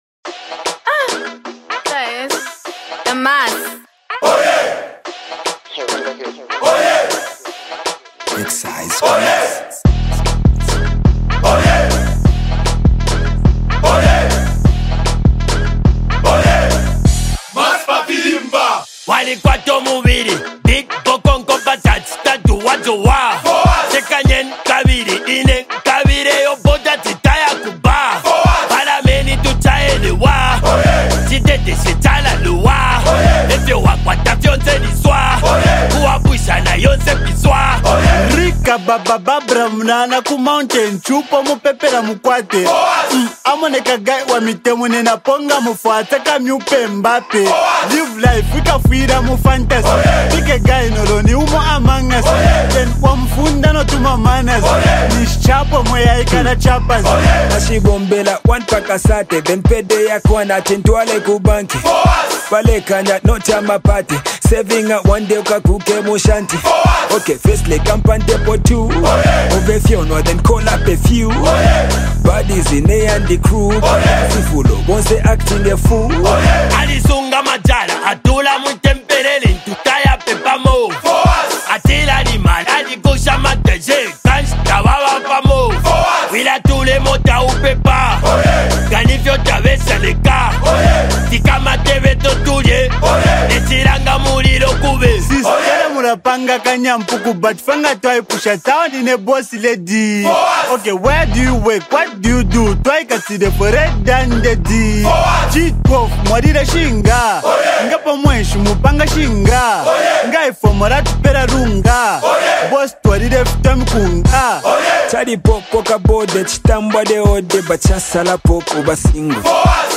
The blend of upbeat rhythms, clever wordplay
vibrant sound